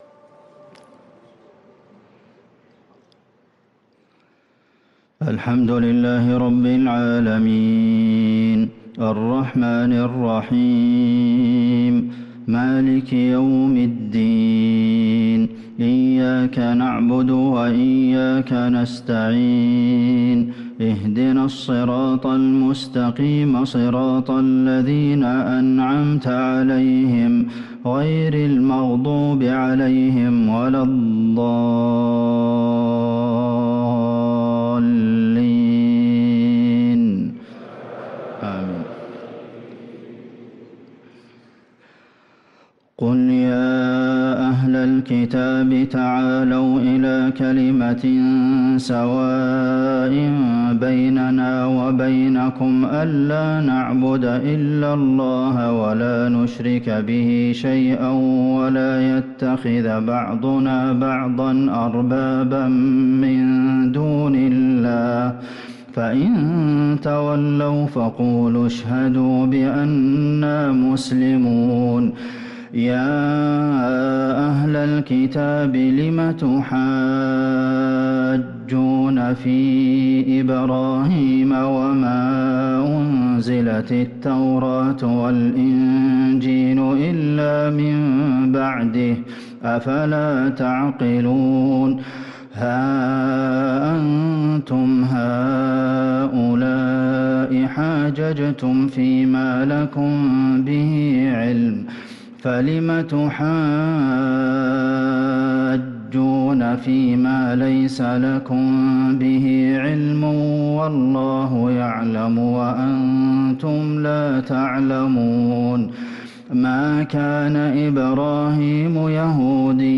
صلاة العشاء للقارئ عبدالمحسن القاسم 17 محرم 1445 هـ
تِلَاوَات الْحَرَمَيْن .